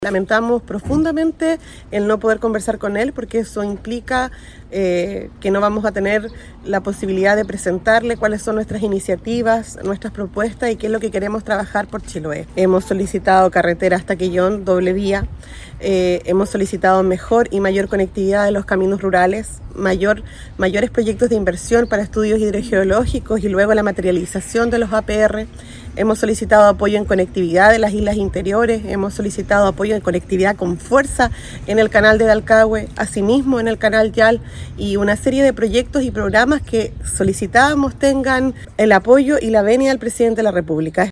La negativa a la opción de plantear personalmente al jefe de Estado los problemas de Chiloé, causó la inmediata respuesta de Javiera Yáñez, presidenta del capítulo de alcaldes, quien indicó que ahora todo quedará bajo la coordinación de ministerios y subsecretarías.